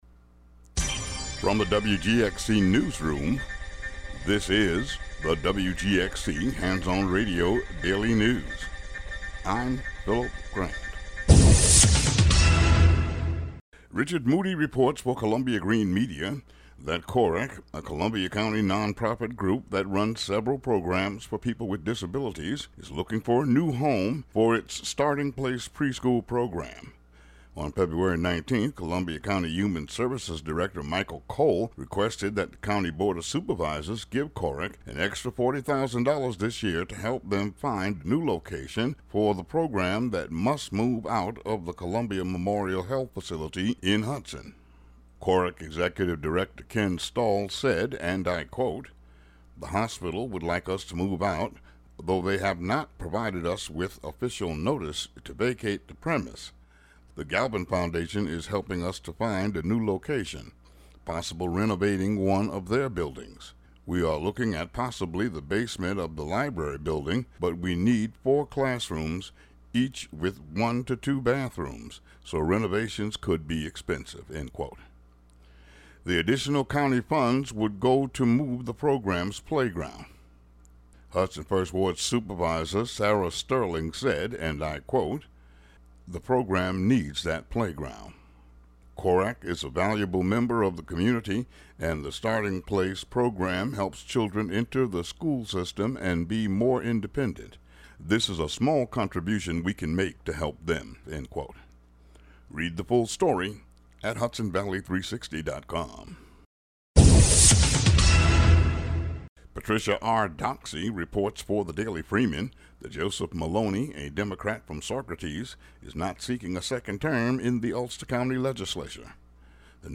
Today's daily news.